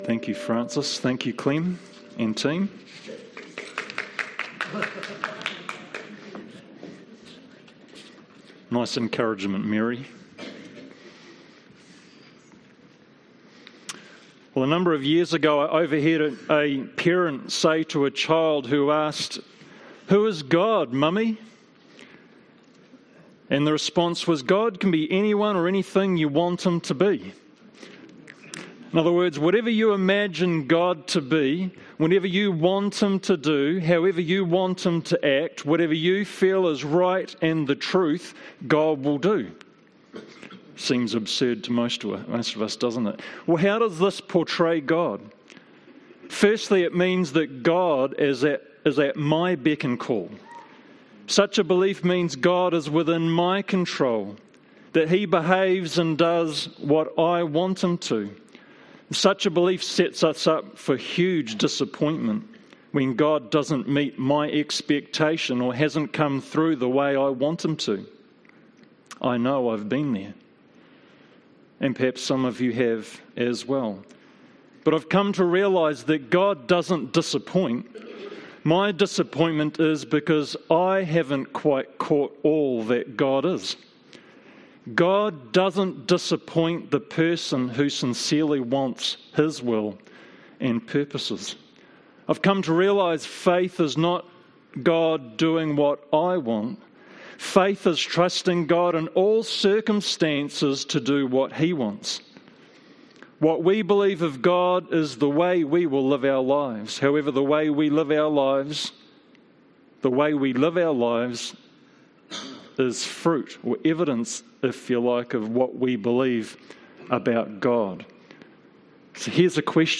Fairfield Baptist Church